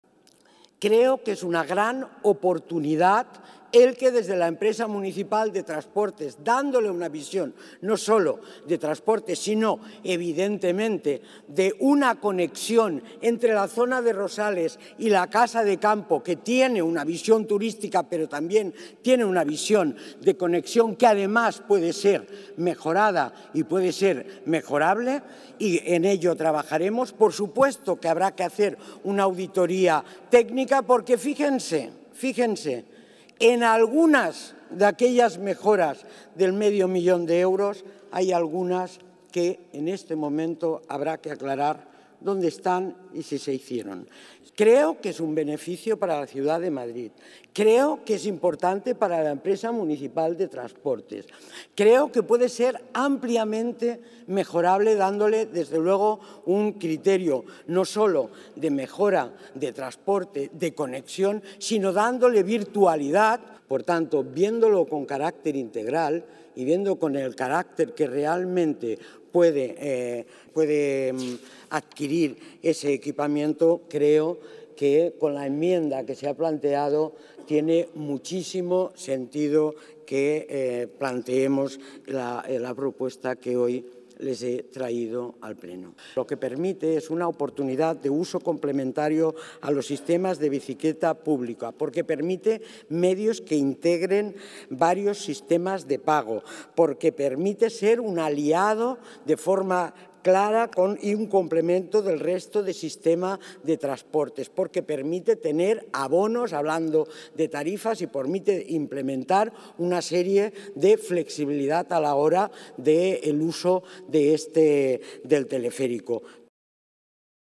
Nueva ventana:Inés Sabanés habla de la nueva gestión del Teleférico por parte de la EMT
SabanesTelefericoIntervencionPleno-31-01.mp3